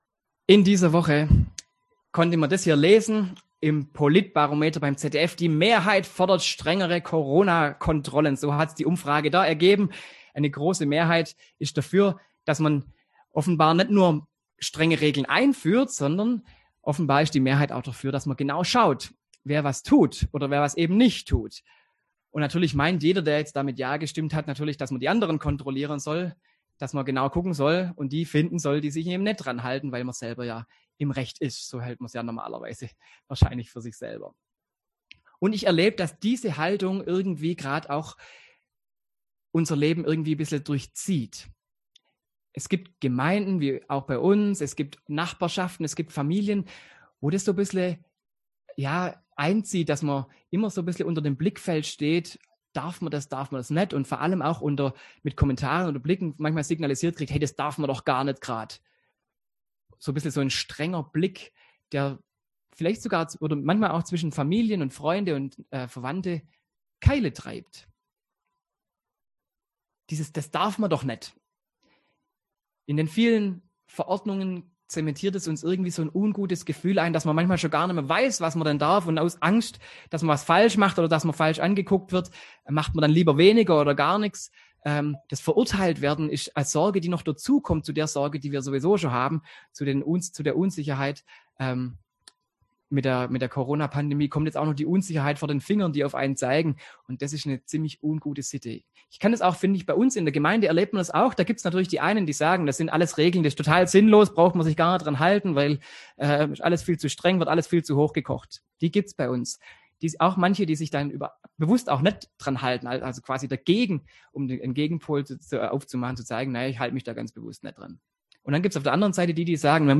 Predigt
im Online-Gottesdienst am 20. Sonntag nach Trinitatis